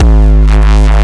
Desecrated bass hit 10.wav